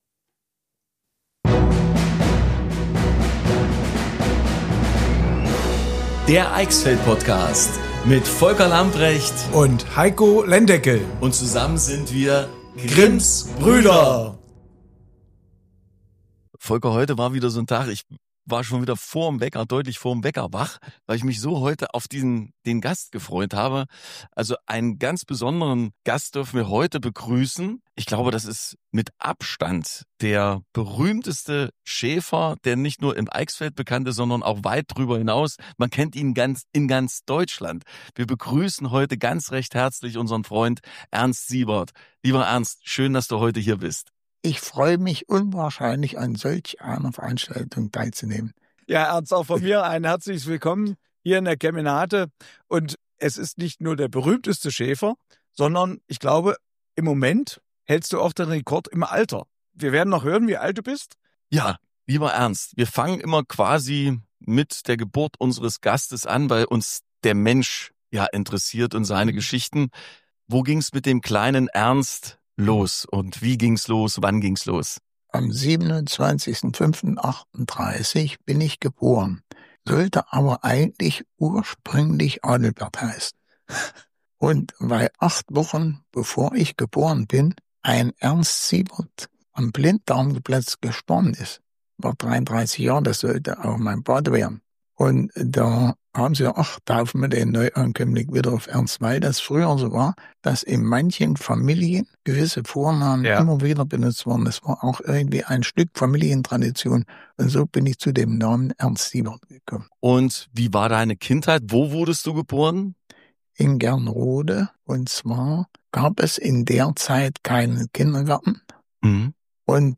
Er erzählt von den Höhen und Tiefen seines Schäferlebens, von kuriosen Begegnungen und von den Abenteuern, die ihn rund um den Globus führten. Humorvoll, ehrlich und voller spannender Geschichten – diese Folge zeigt, dass es nie zu spät ist, die Welt zu entdecken.